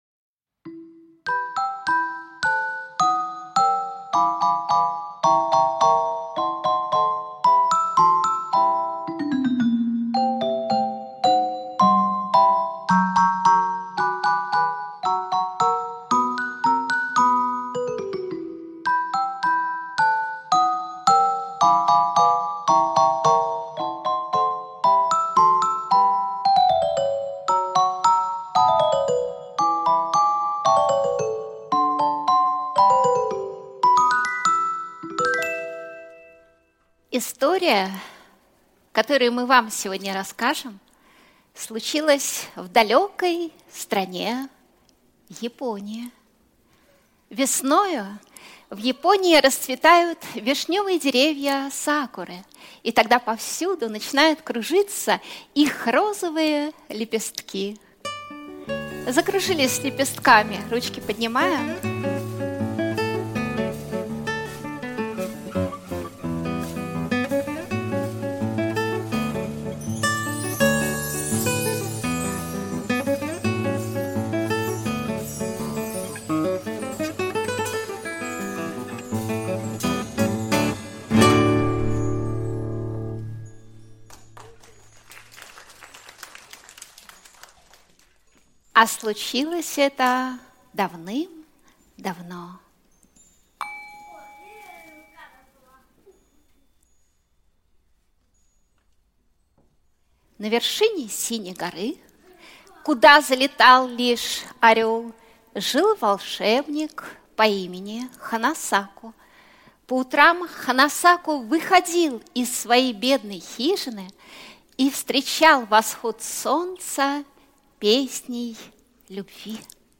Аудиокнига Сакура счастья | Библиотека аудиокниг